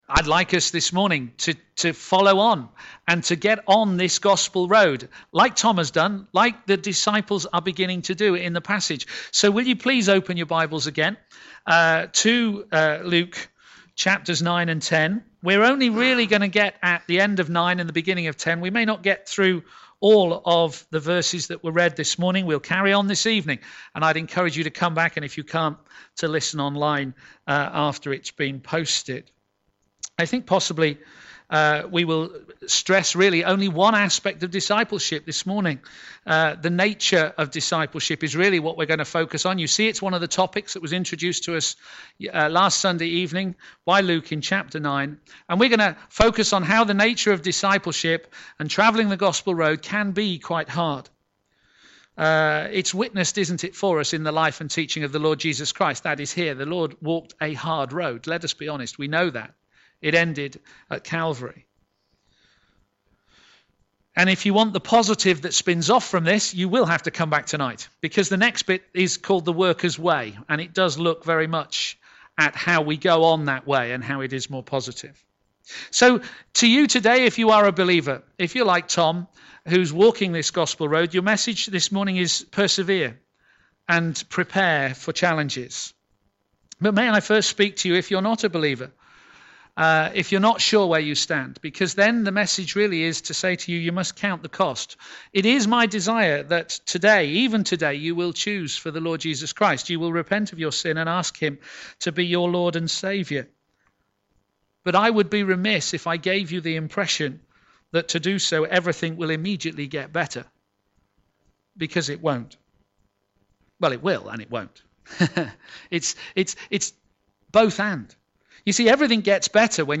a.m. Service
The Gospel Road is Hard Sermon